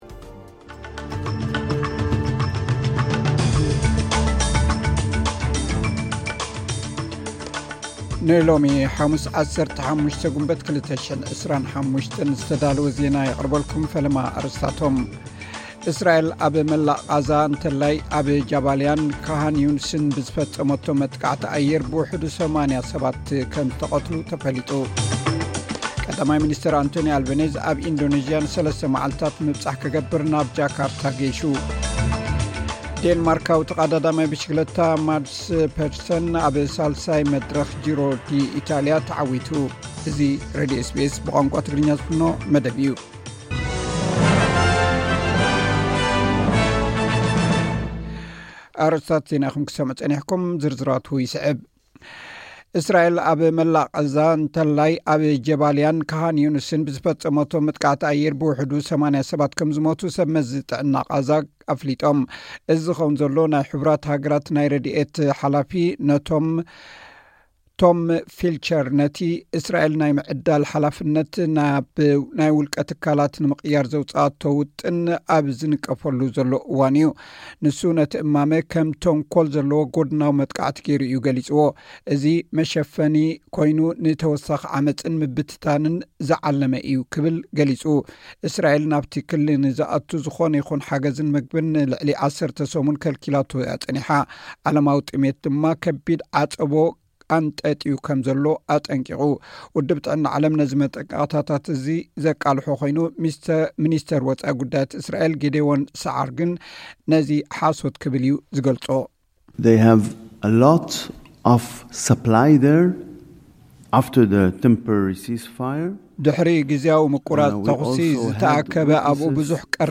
ዕለታዊ ዜና ኤስ ቢ ኤስ ትግርኛ (15 ግንቦት 2025)